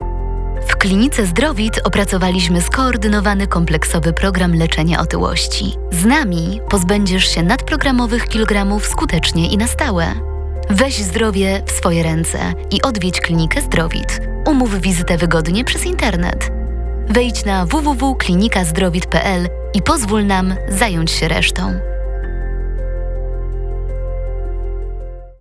Udzielam głosu do nagrań lektorskich – audiobooki, teksty medytacyjne, reklamy, filmy instruktażowe, dokumentalne i fabularne.